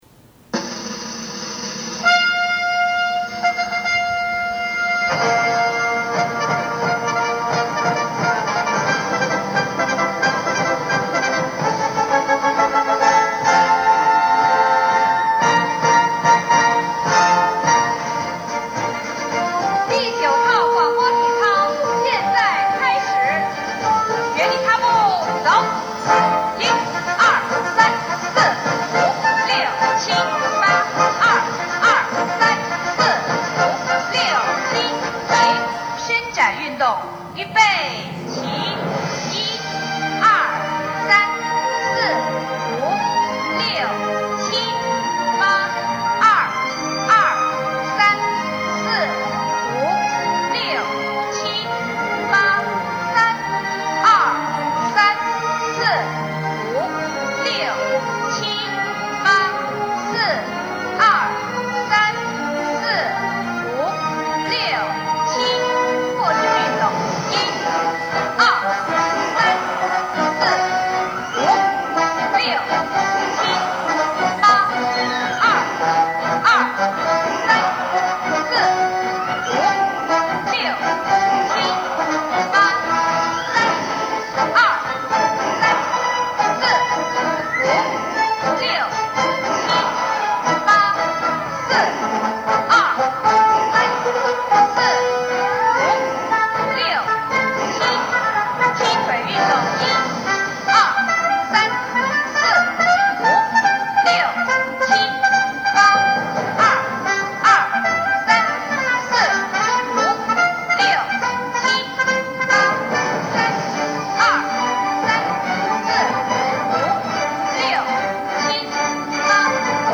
Shanghai, China. Morning Exercise Broadcast on the ECNU Campus. 9:39 am.